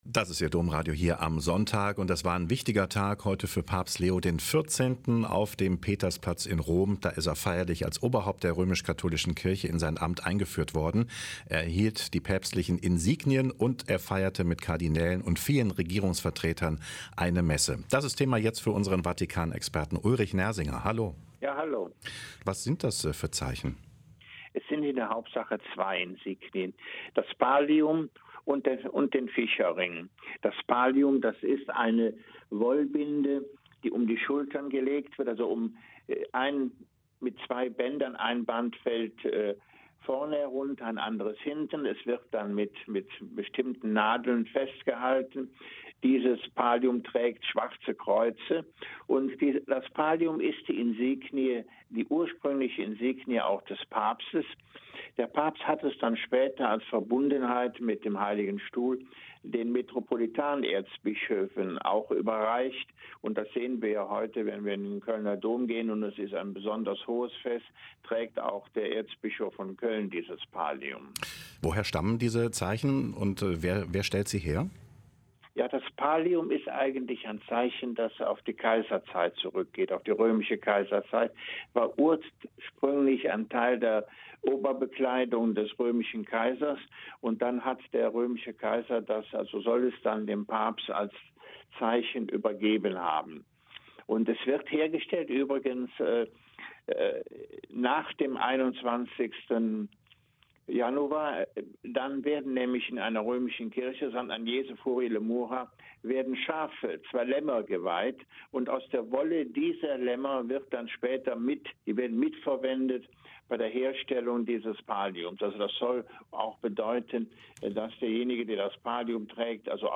Ein Interview mit